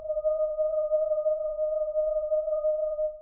Percussion
alien1_ff.wav